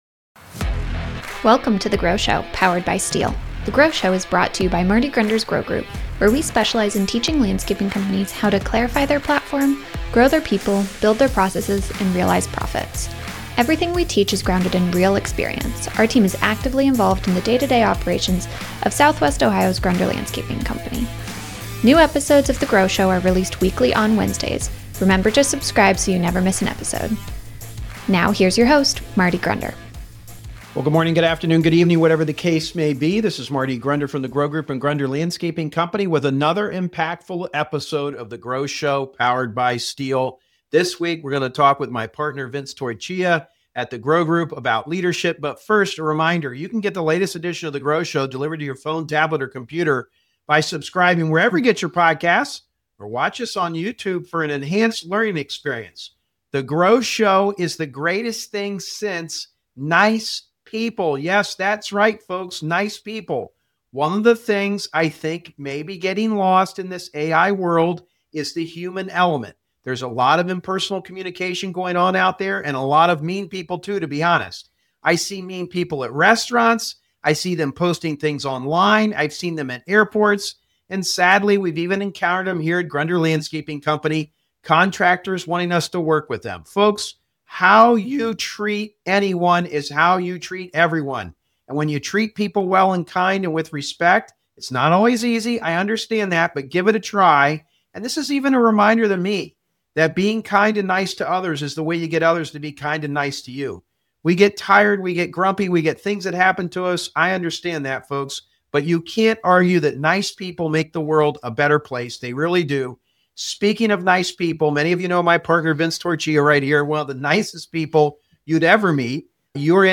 Interview Series: Leadership in Landscaping